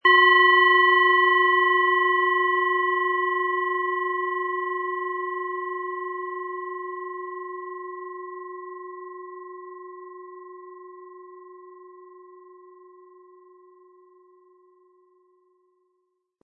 Planetenton
Diese Planeten-Klangschale Jupiter wurde in überlieferter Weise von Hand gearbeitet.
Wie klingt die Schale?
Sie möchten den schönen Klang dieser Schale hören? Spielen Sie bitte den Originalklang im Sound-Player - Jetzt reinhören ab.
Spielen Sie die Schale mit dem kostenfrei beigelegten Klöppel sanft an und sie wird wohltuend erklingen.